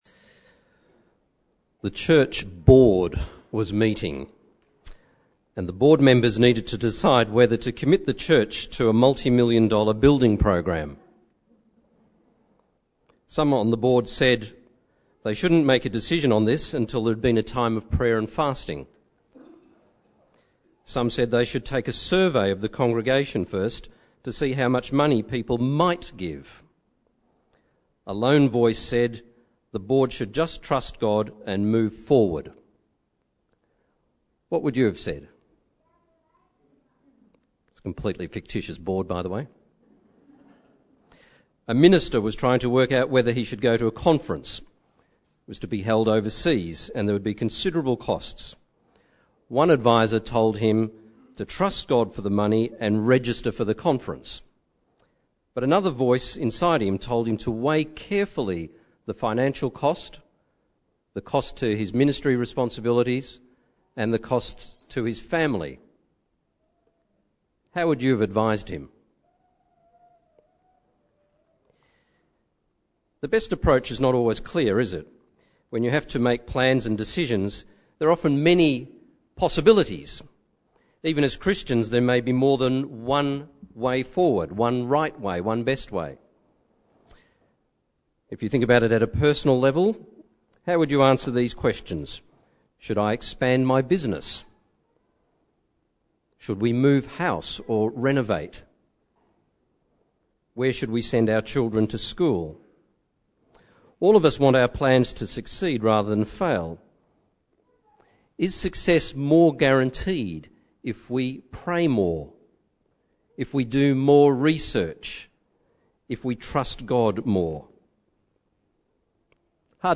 Download Download Bible Passage Nehemiah 2:1-20 In this sermon